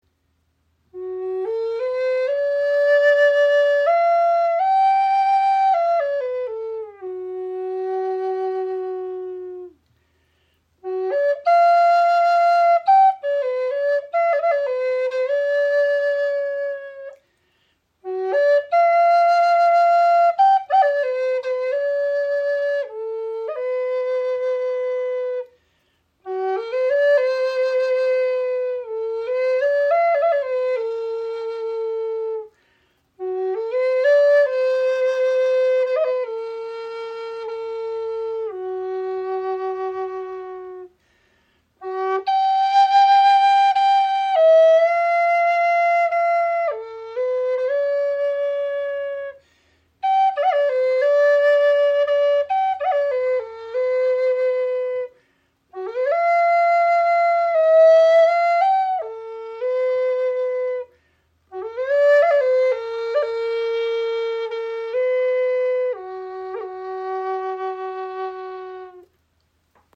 Gebetsflöte aus einem Ast in G – 432 Hz | Nature Spirit | Teakholz 52 cm
Mit 52 cm Länge liegt sie angenehm in der Hand und klingt in G auf 432 Hz – der Ton des Halschakras, der Deiner Stimme einen neuen Klang der Liebe verleiht und Harmonie, Balance sowie spirituelles Wachstum unterstützt.
Ihr klarer, erhebender Klang macht sie zum Allrounder, der sich mühelos mit vielen anderen Instrumenten kombinieren lässt.